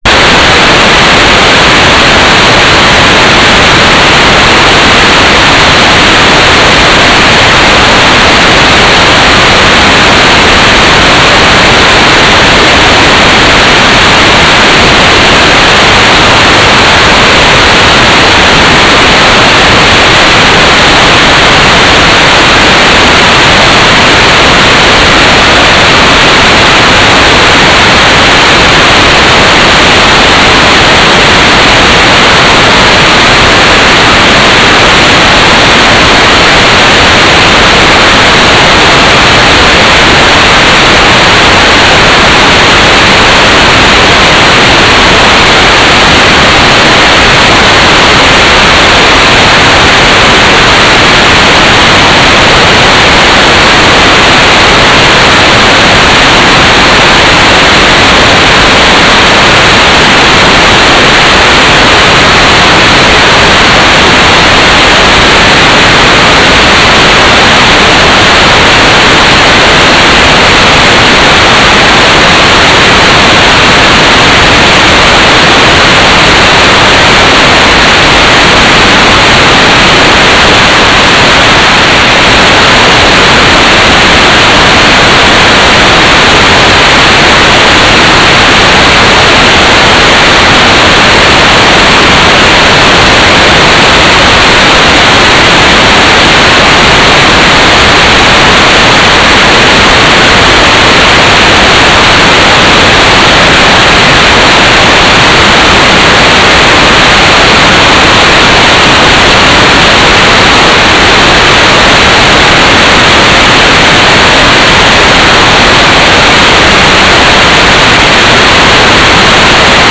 "transmitter_description": "GMSK4k8 Telemetry",
"transmitter_mode": "GMSK",